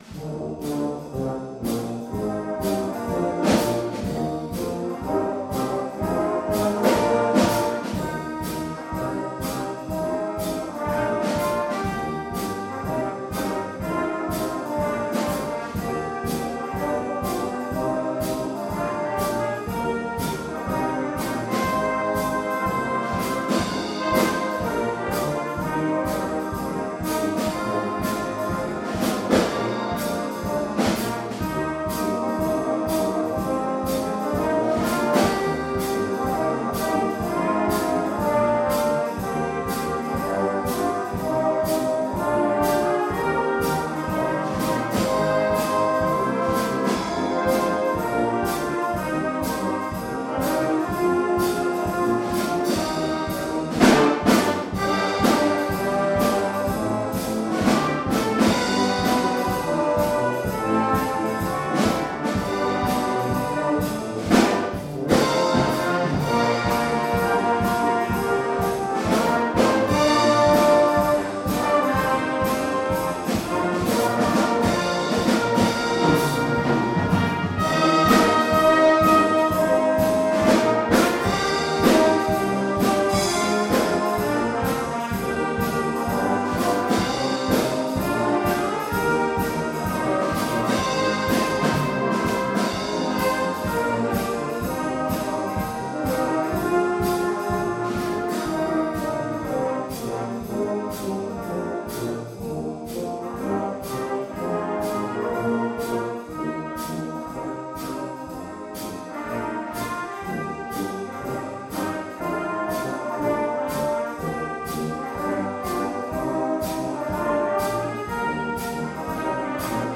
The following pieces were recorded at our concert at Foxearth Church on 19th September 2015